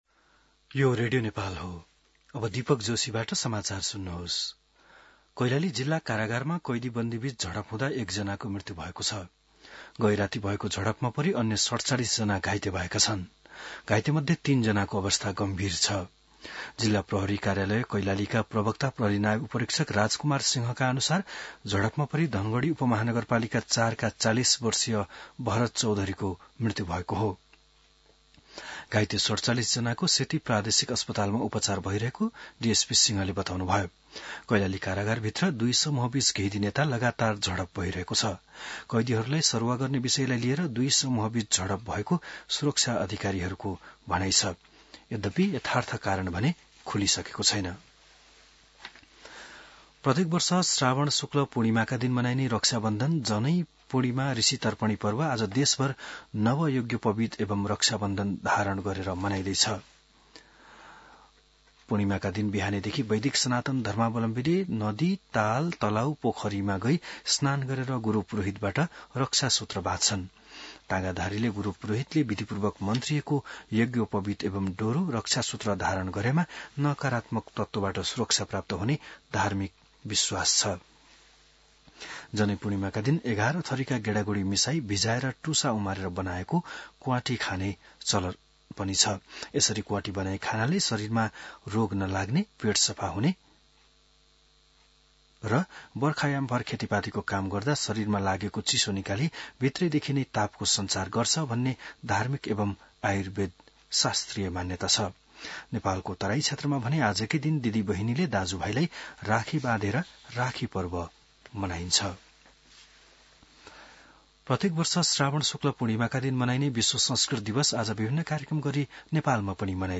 बिहान १० बजेको नेपाली समाचार : २४ साउन , २०८२